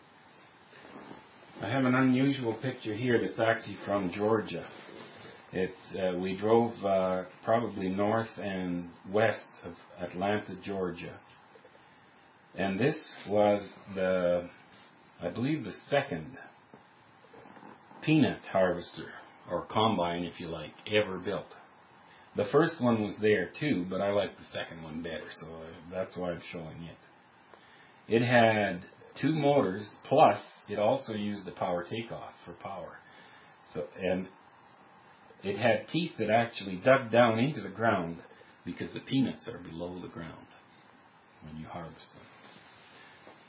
Peanut Harvester - Sound Clip